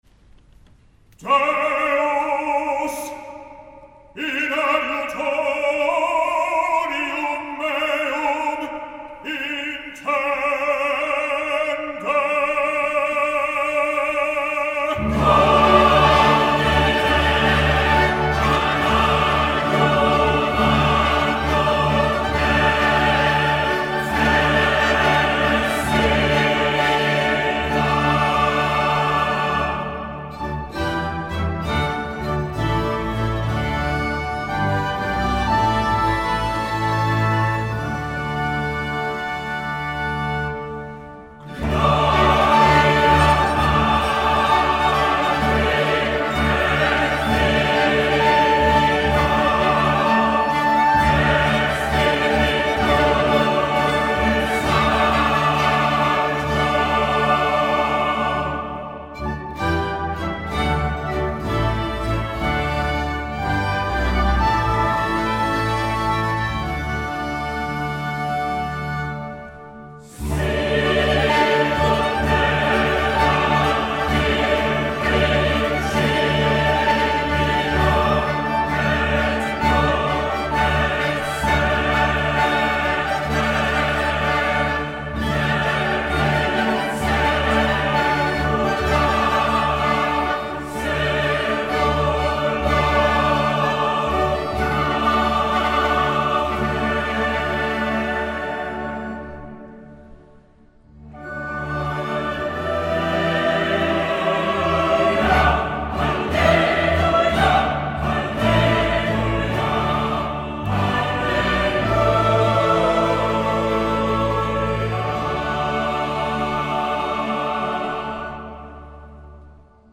The Monteverdi Choir’s 50th Anniversary Concert
Live from King’s College, Cambridge
Boy Choristers from King’s College Chapel, Cambridge
Sir John Eliot Gardiner